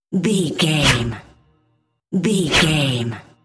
Airy swish pass by fast
Sound Effects
Fast
futuristic
pass by
sci fi
vehicle